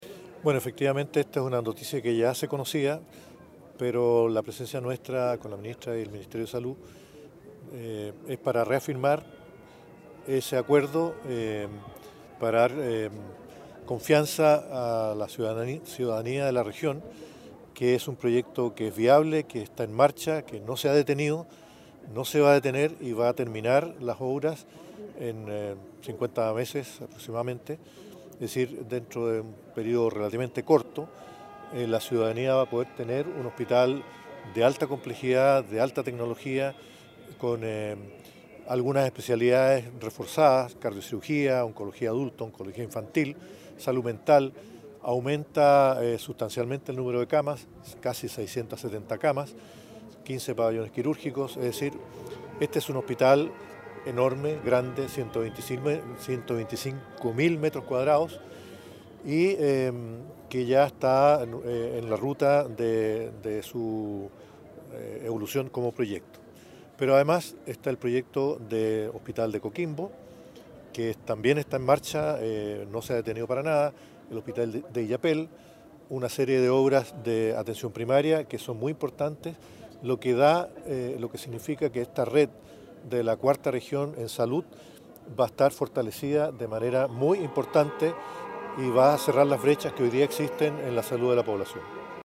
audio-Subsecretario.mp3